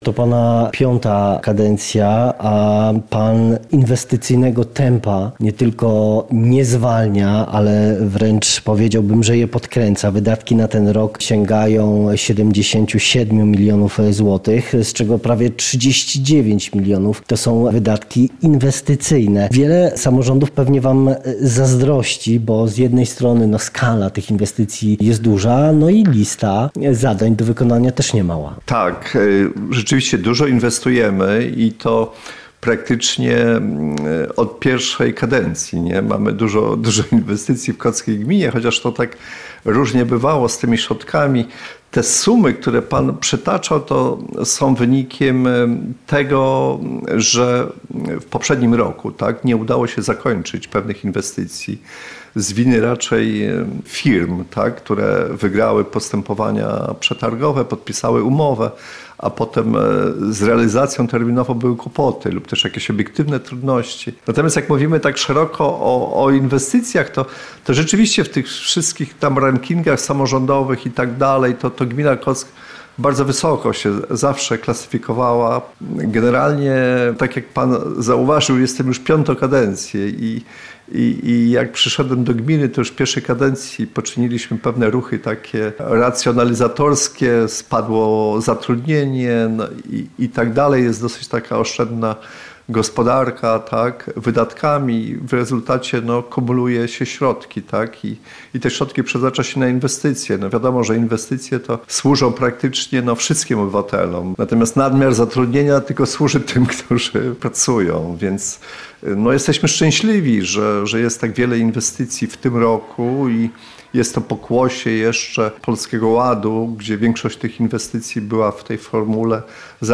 z burmistrzem Kocka Tomaszem Futerą rozmawia